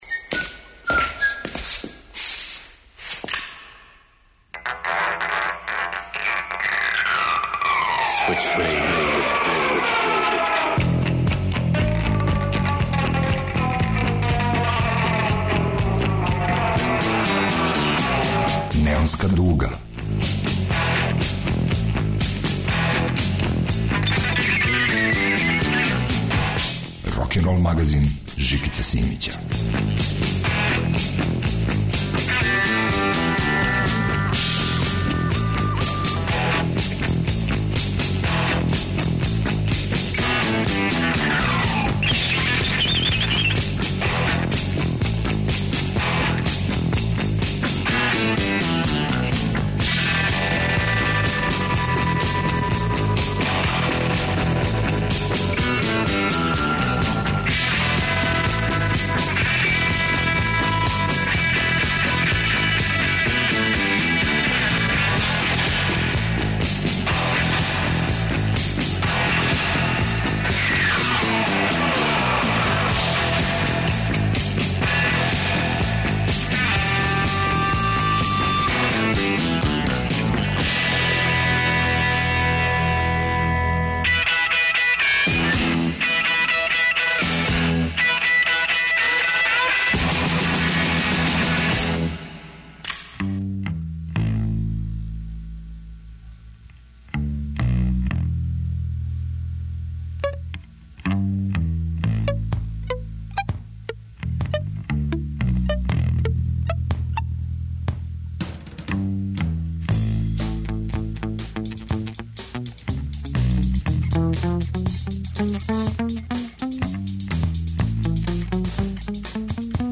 Рокенрол као музички скор за живот на дивљој страни. Вратоломни сурф кроз време и жанрове.